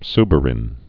(sbər-ĭn)